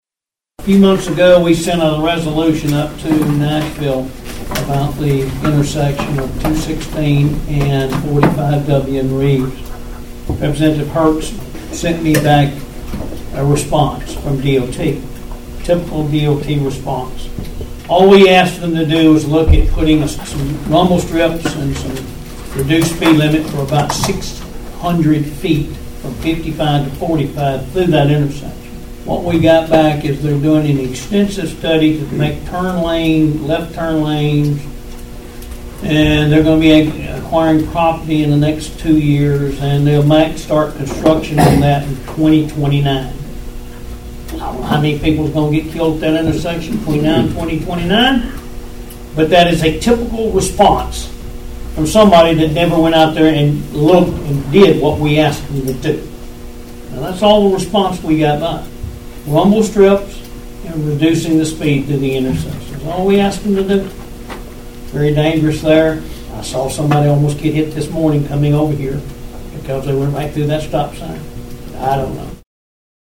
Concern was raised at Monday’s Obion County Commission meeting pertaining to a Department of Transportation response to a dangerous intersection.
Commissioner Danny Jowers delivered the response from TDOT concerning a request for a location that has been the site of fatal accidents.(AUDIO)